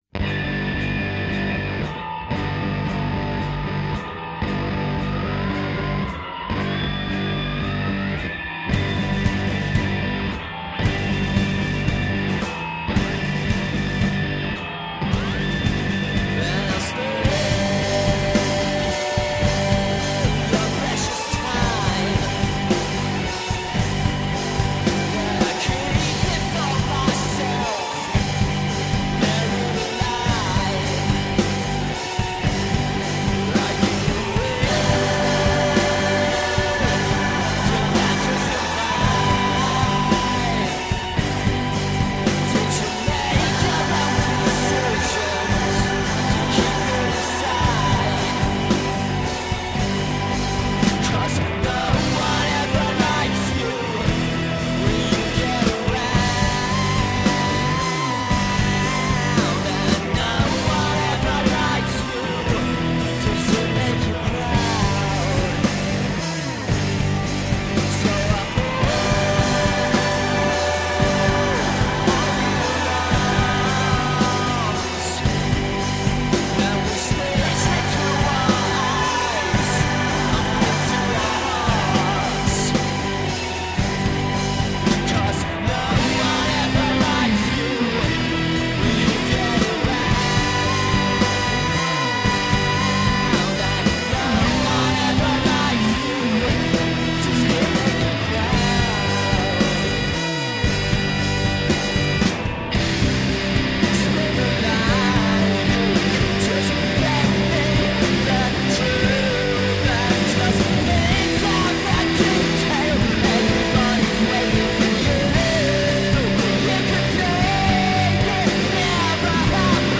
Here’s a massive track, enjoy.